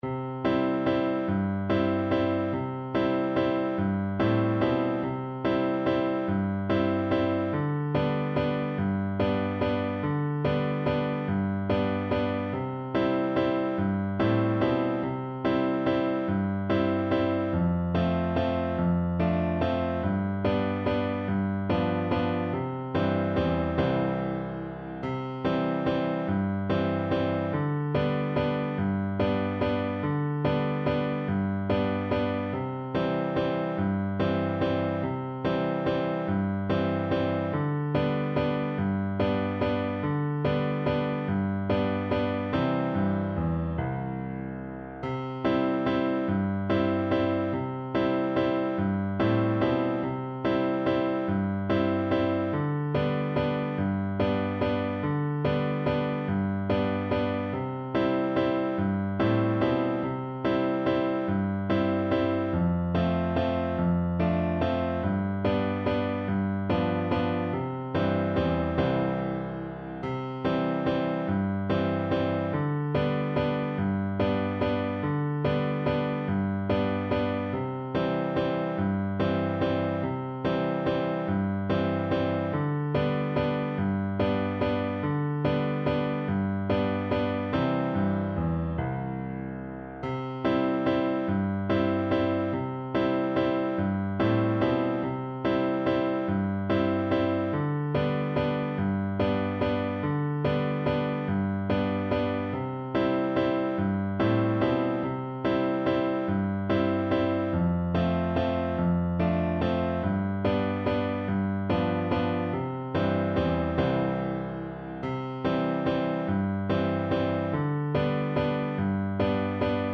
Steady one in a bar .=c.48
3/8 (View more 3/8 Music)